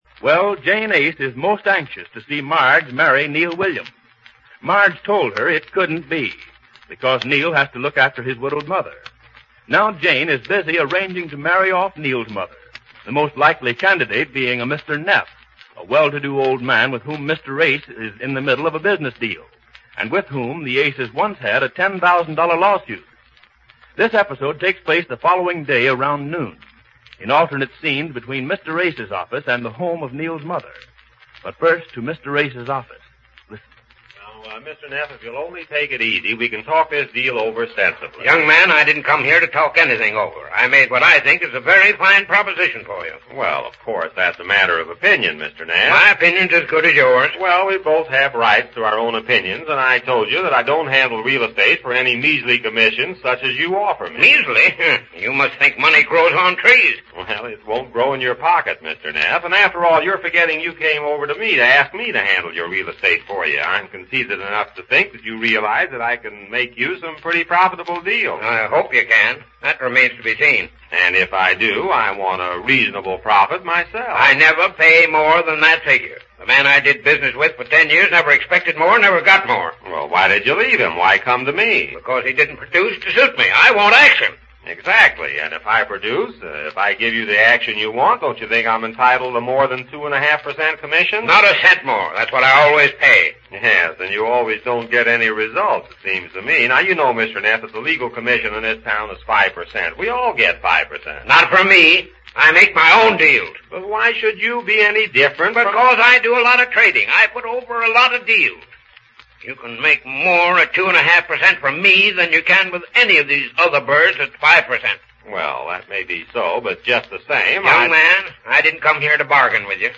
Easy Aces Radio Program, Starring Goodman Aiskowitz and Jane Epstein-Aiskowitz